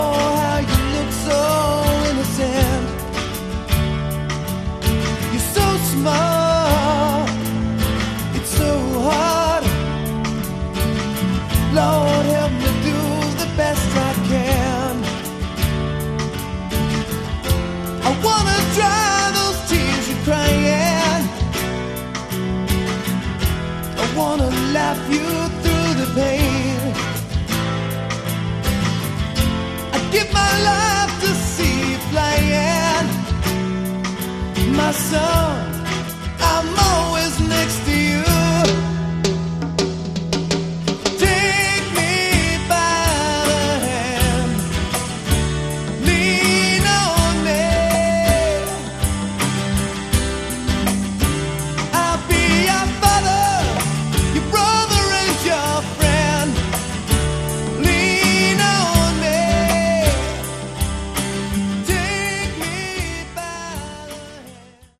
Category: Hard Rock
lead vocals
guitar, keyboards
bass
drums